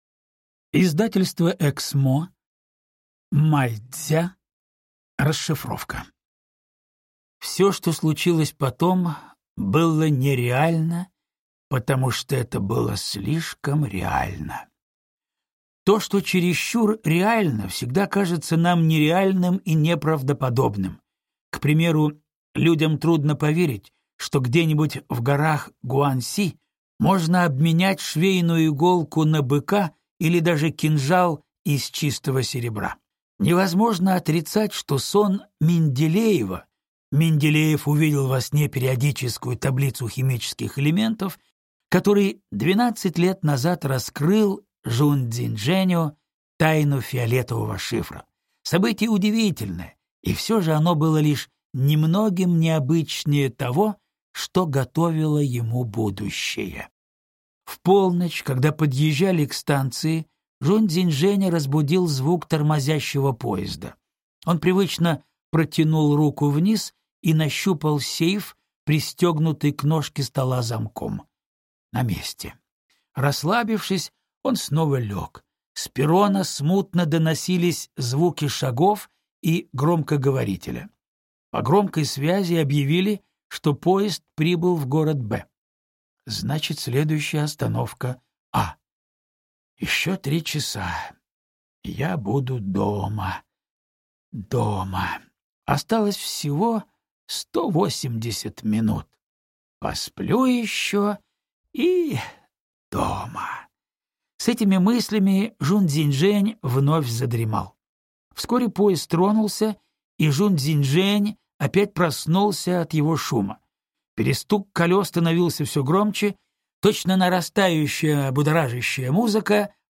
Аудиокнига Расшифровка | Библиотека аудиокниг
Прослушать и бесплатно скачать фрагмент аудиокниги